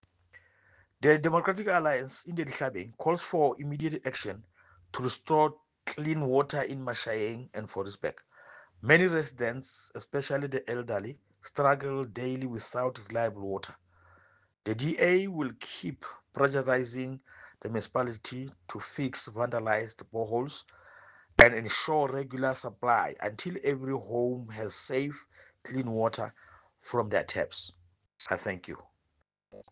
English and Sesotho soundbites by Cllr Lucas Xaba.